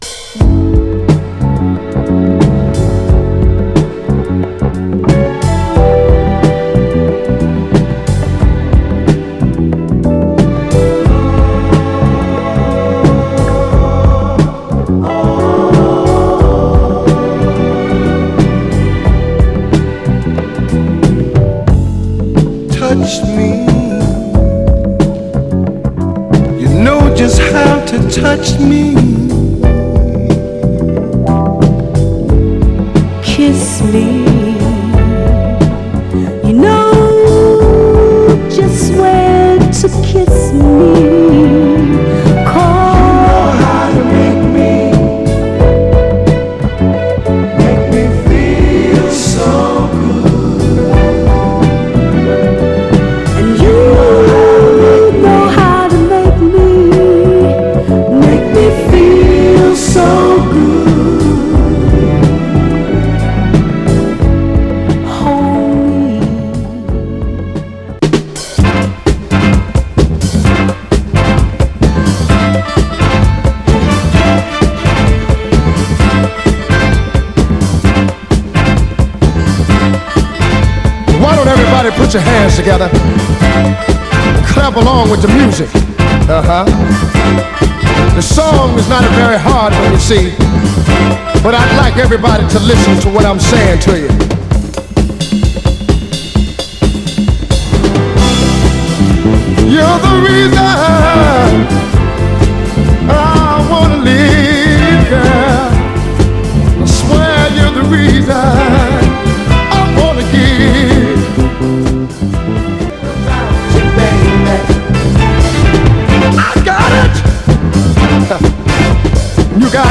盤は細かいスレありますが、グロスが残っておりプレイ良好です。
※試聴音源は実際にお送りする商品から録音したものです※